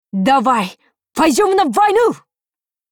Cv-70204_battlewarcry.mp3